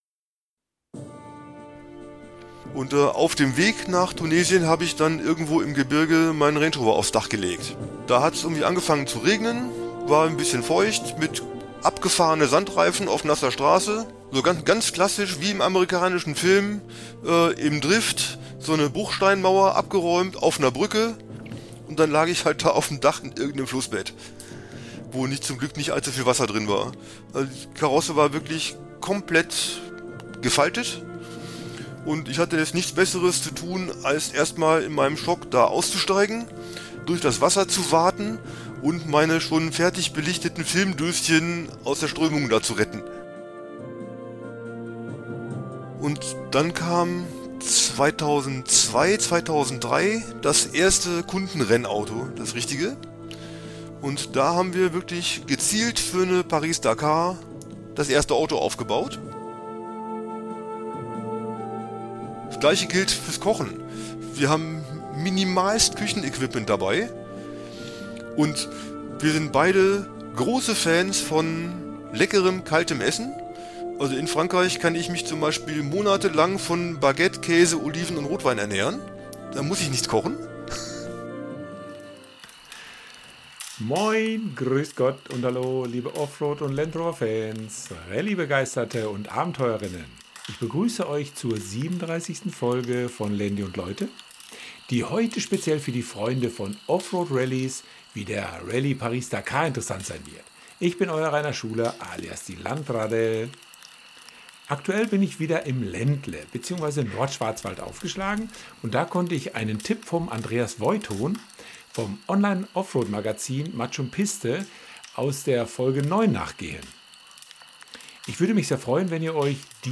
Wen wundert es da, das heute unser Online-Interview mal direkt ohne technische Probleme gestartet ist.